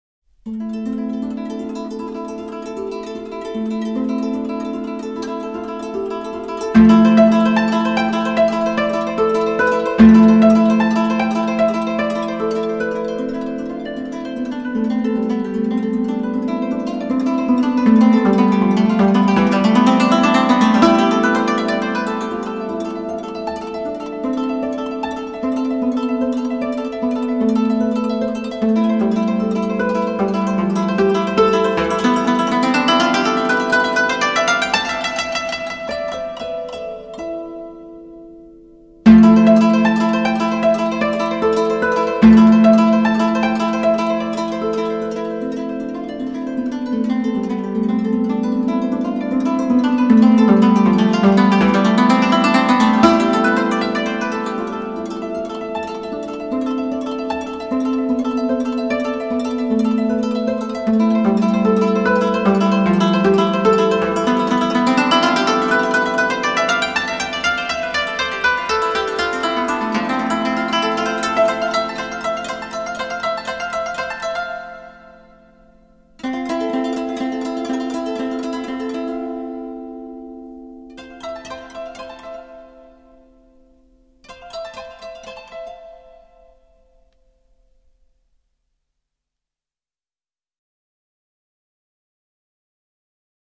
二十五弦筝
因此它的音域要宽得多，甚至可以让你联想到竖琴。
呵呵，除了CD本身的质量优秀以外，古筝特有的纯净的音色，很能带动音响最纯的一面。